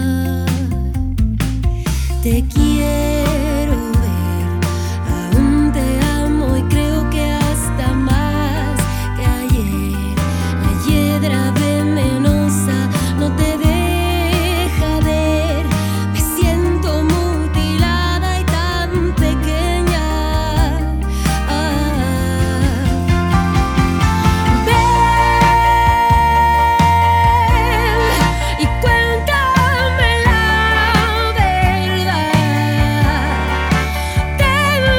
# Alternativo & Rock Latino